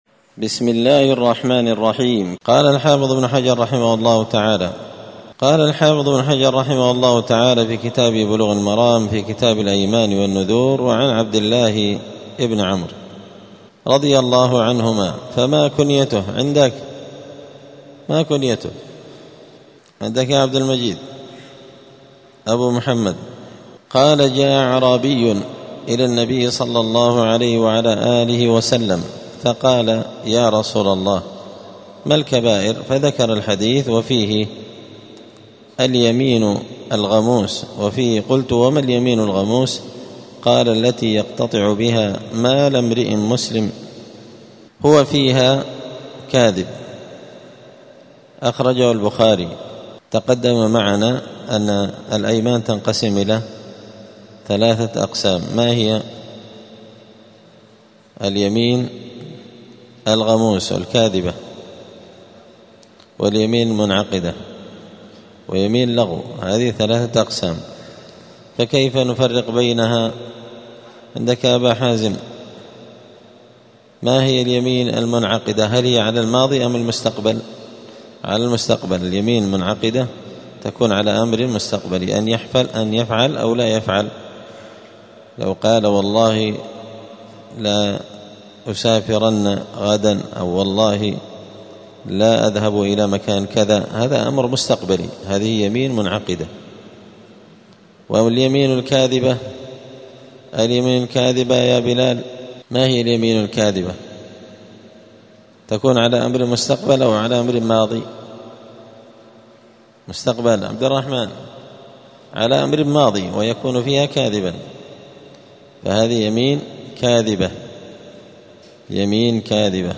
*الدرس الثامن (8) {اﻟﻴﻤﻴﻦ اﻟﻐﻤﻮﺱ}*
دار الحديث السلفية بمسجد الفرقان قشن المهرة اليمن